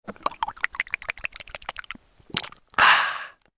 دانلود آهنگ آب 7 از افکت صوتی طبیعت و محیط
جلوه های صوتی
دانلود صدای آب 7 از ساعد نیوز با لینک مستقیم و کیفیت بالا